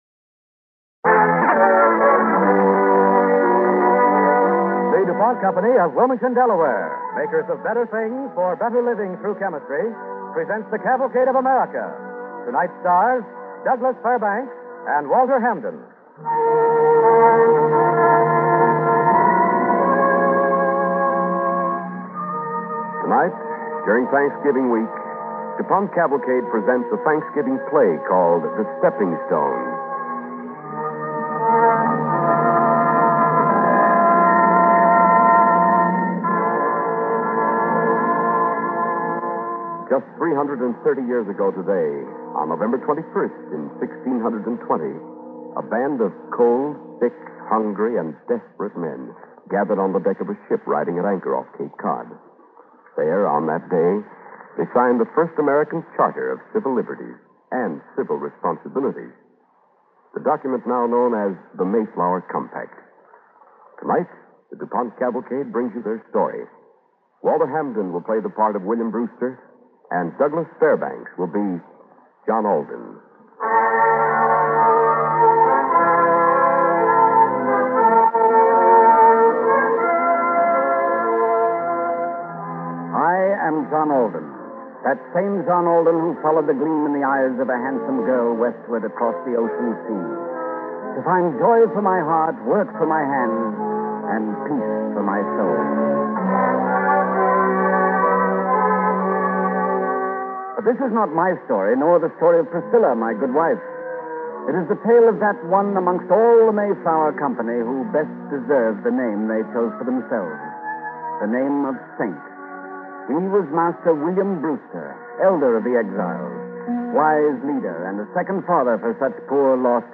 starring Douglas Fairbanks Jr.